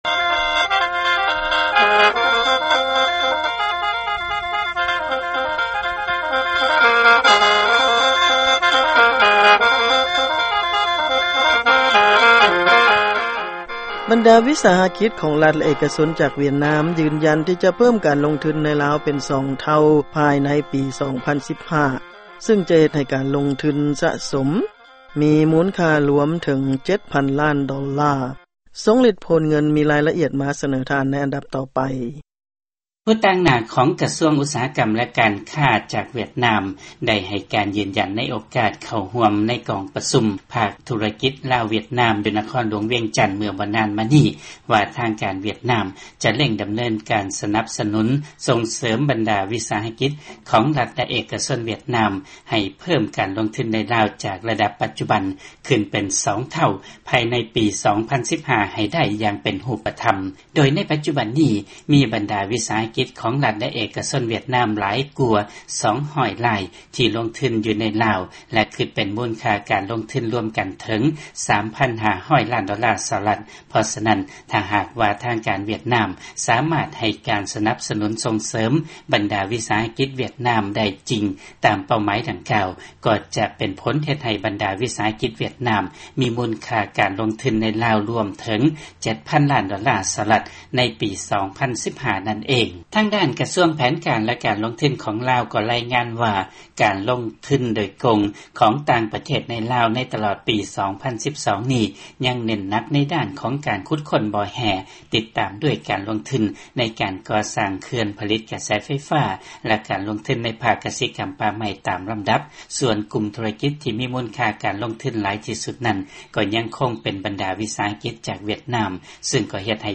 ຟັງລາຍງານຂ່າວການຄ້າລາວ ແລະຫວຽດນາມ